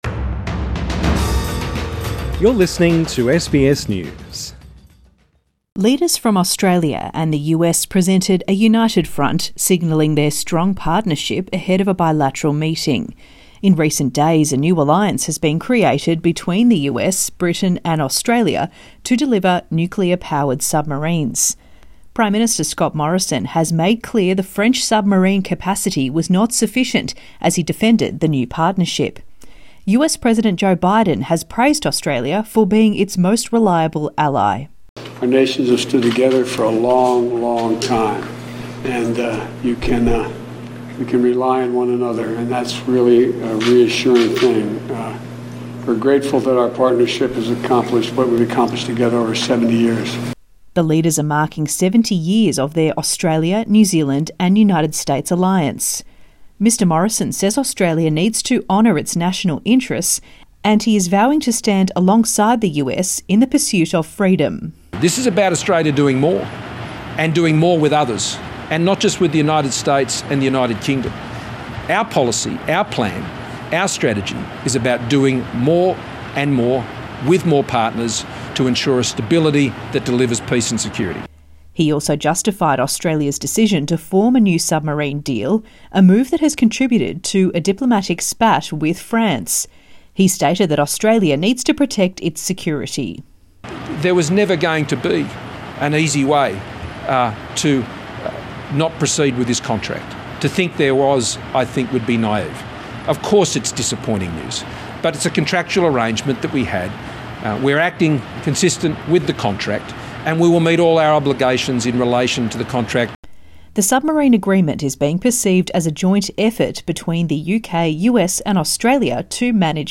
Scott Morrison speaks to the media in Washington DC Source: SBS